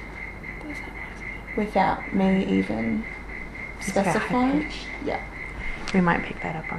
We captured EVP’s during our client interview prior to our equipment being fully setup.
EVP 2 –This clip sounds like faint female whispering to us.
EVP6_female-whisperin.wav